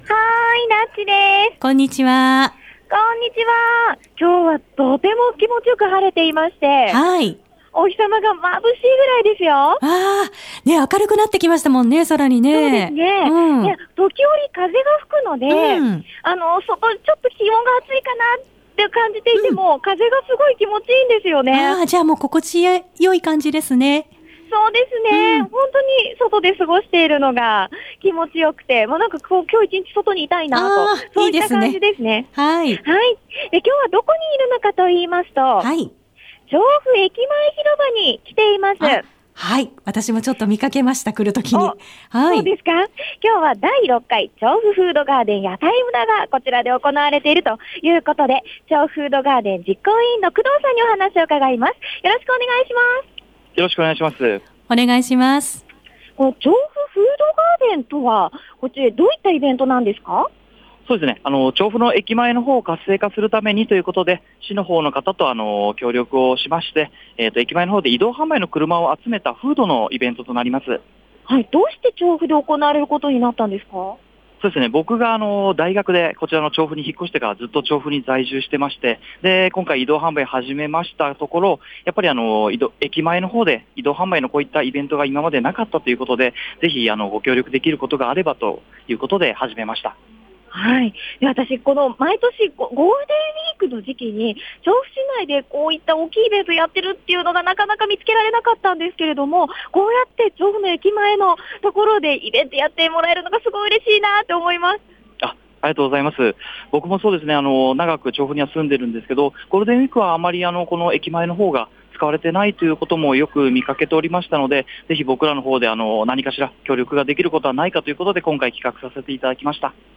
街角レポート